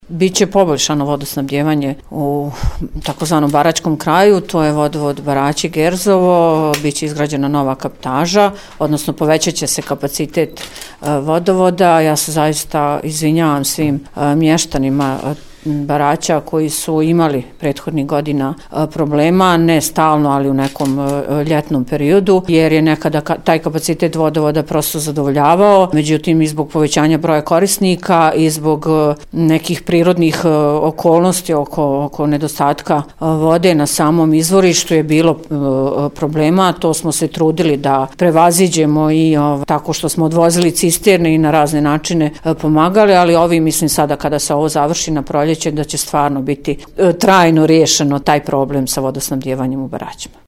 izjavu
DIVNA-ANICIC-NACELNIK-VODOVOD.mp3